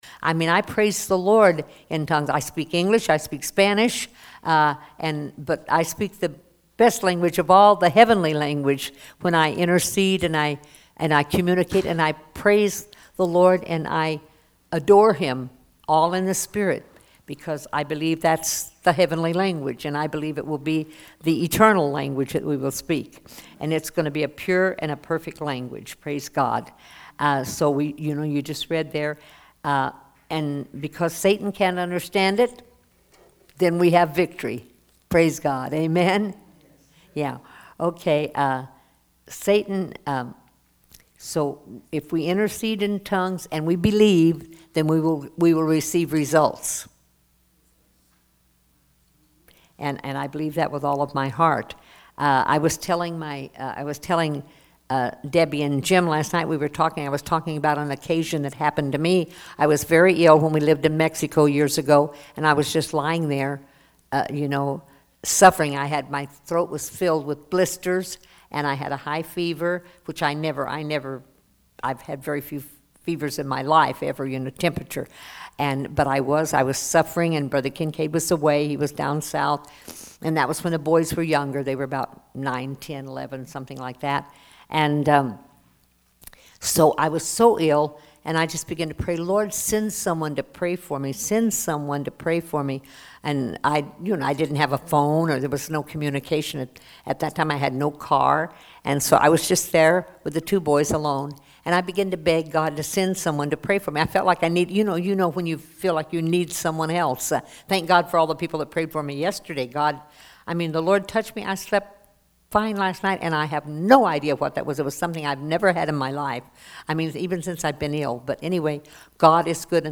Sunday School Lesson: The Holy Spirit
(Partial Recording)